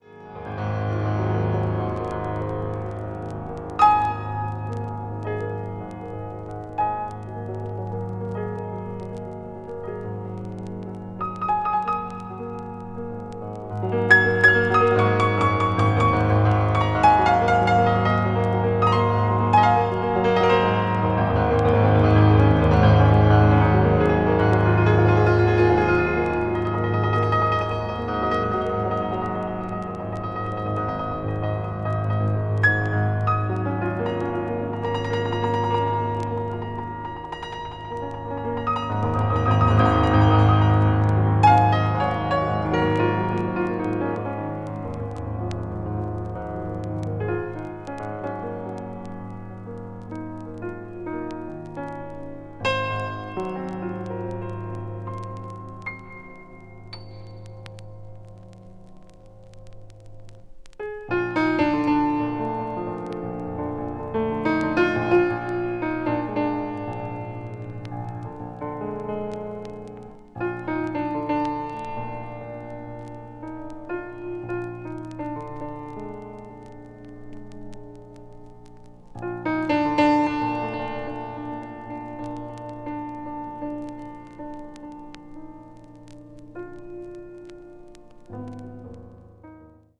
A面は空間や余韻を保ちながら終始ゆったりとしたペースで演奏。